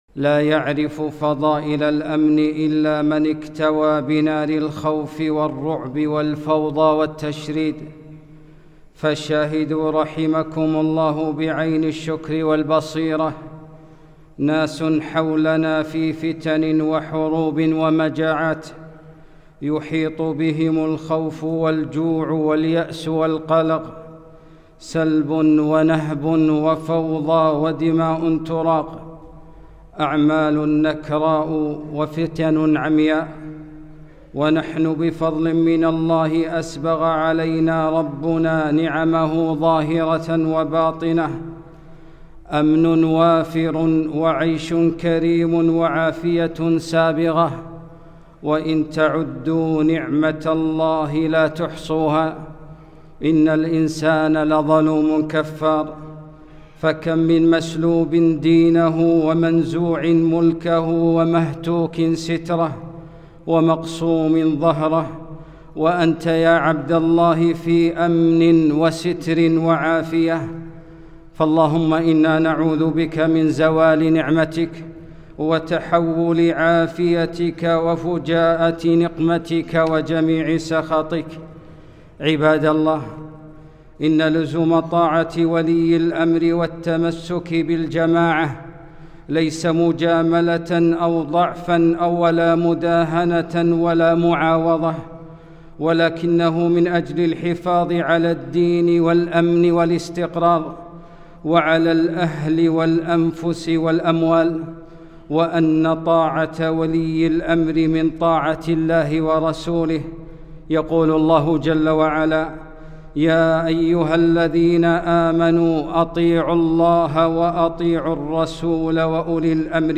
خطبة - السعيد من اتعظ بغيره